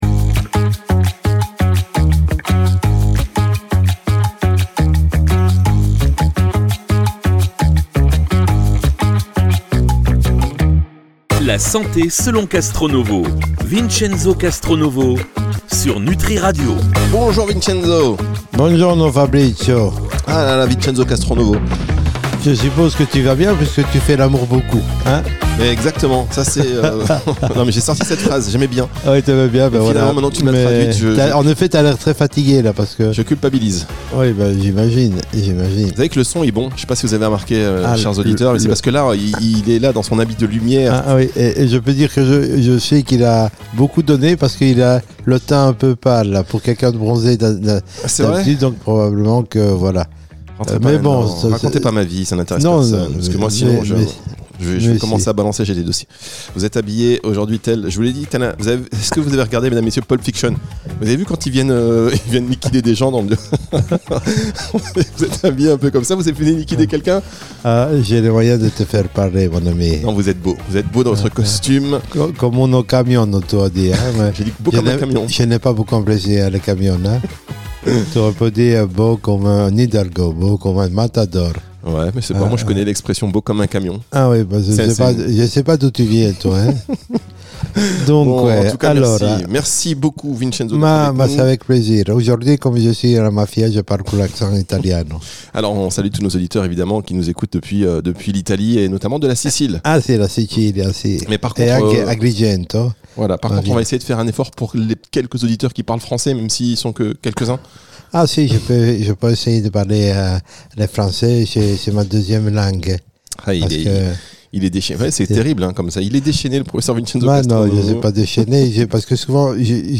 Entre éclats de rire et explications limpides, on découvre pourquoi l’hyperinsulinisme est au cœur de nombreuses pathologies modernes et comment notre évolution — jusqu’à l’époque de l’esclavage — influence encore aujourd’hui notre manière de stocker le sucre. Un échange vif, accessible et instructif sur les liens entre biologie, alimentation et histoire humaine.